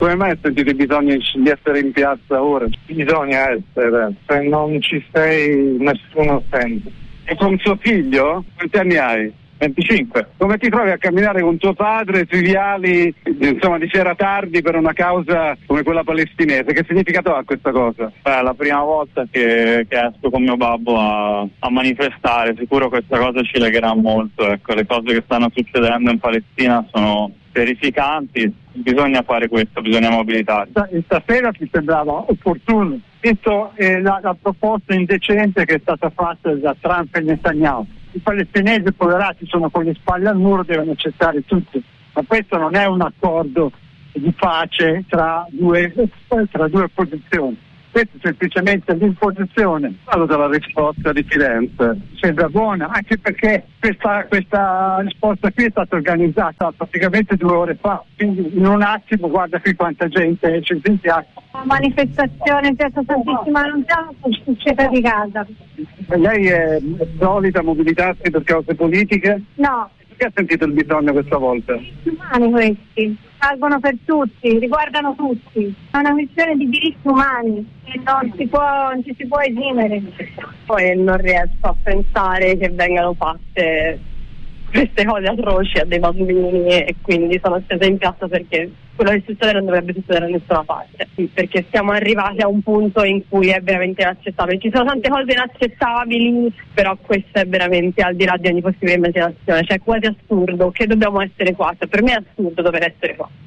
Il corteo di Firenze per Gaza e Sumud Flotilla. Le interviste